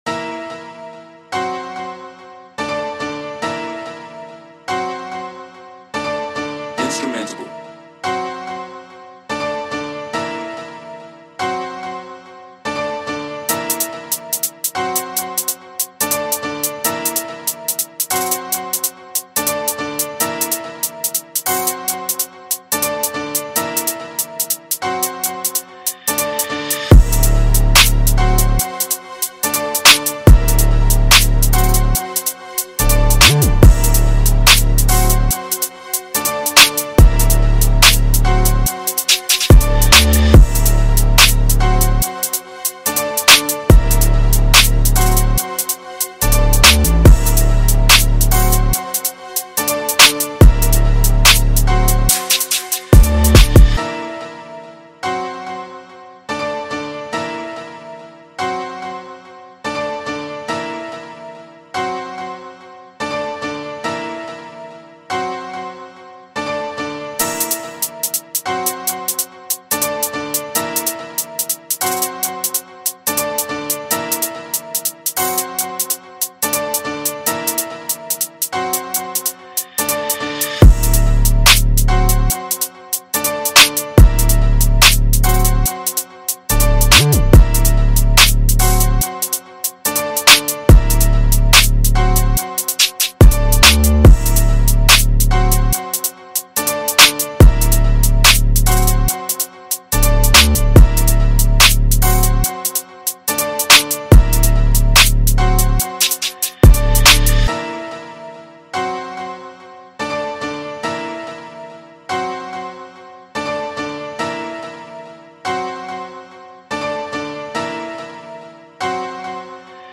Drill Type Beat Instrumental